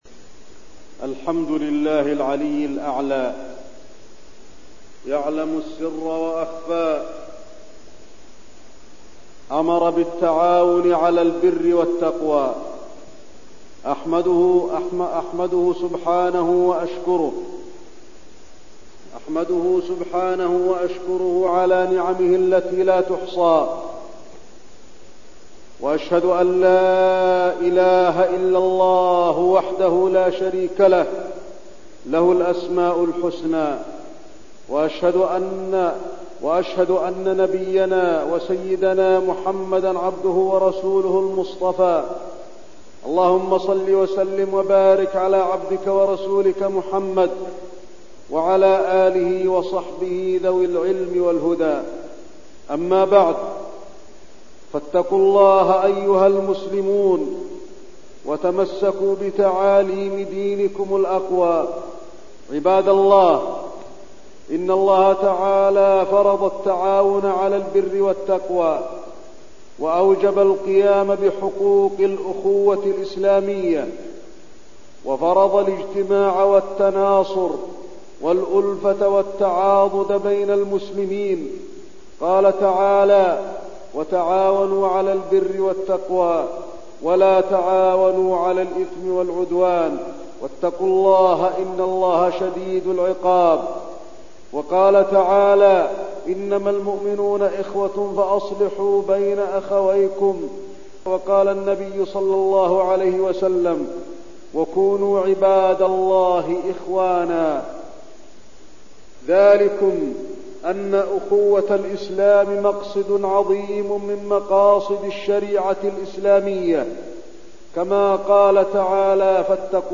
تاريخ النشر ٢٨ شوال ١٤١٢ هـ المكان: المسجد النبوي الشيخ: فضيلة الشيخ د. علي بن عبدالرحمن الحذيفي فضيلة الشيخ د. علي بن عبدالرحمن الحذيفي الأخوة الإسلامية